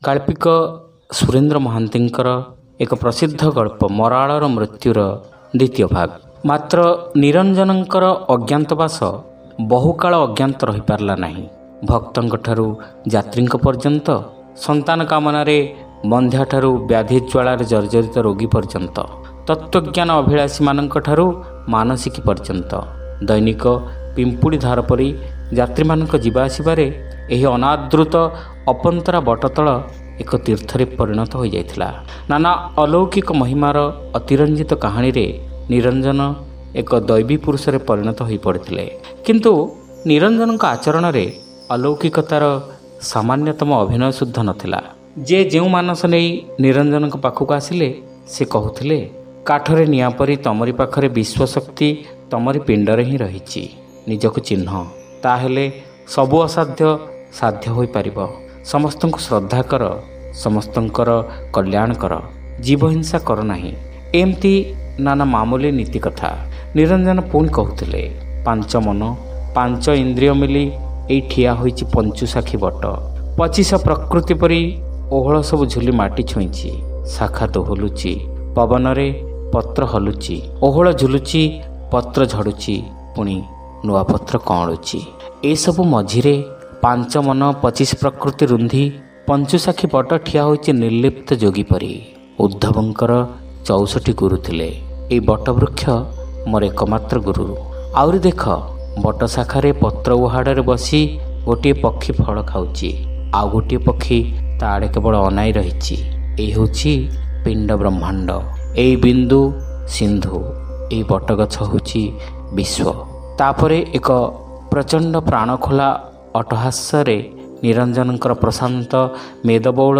ଶ୍ରାବ୍ୟ ଗଳ୍ପ : ମରାଳର ମୃତ୍ୟୁ (ଦ୍ୱିତୀୟ ଭାଗ)